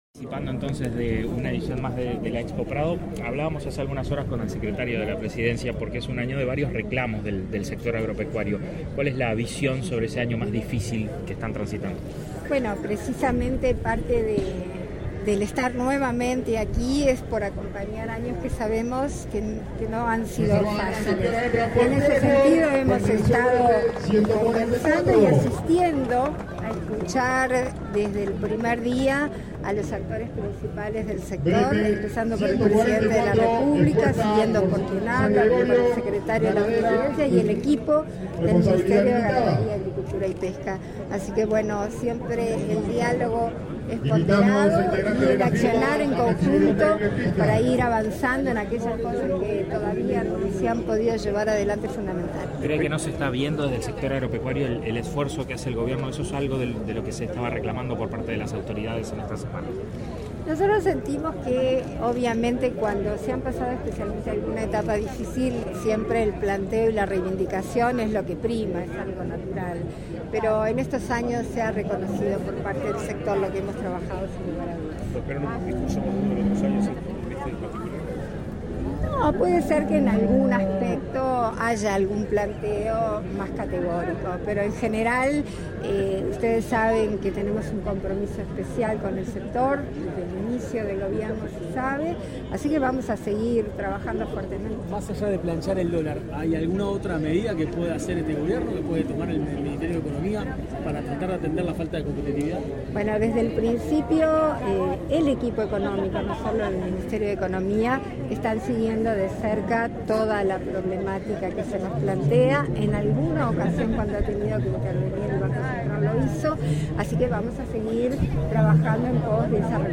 Declaraciones a la prensa de la presidenta de la República en ejercicio, Beatriz Argimón
Declaraciones a la prensa de la presidenta de la República en ejercicio, Beatriz Argimón 13/09/2023 Compartir Facebook X Copiar enlace WhatsApp LinkedIn Tras participar en las premiaciones Gran Campeón Aberdeen-Angus y Gran Campeona Polled Hereforden, en la Expo Prado 2023, este 13 de setiembre, la presidenta de la República en ejercicio, Beatriz Argimón, realizó declaraciones a la prensa.
argimon prensa.mp3